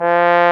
Index of /90_sSampleCDs/Roland L-CDX-03 Disk 2/BRS_Bs.Trombones/BRS_Bs.Bone Solo